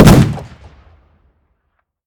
shotgun-shot-3.ogg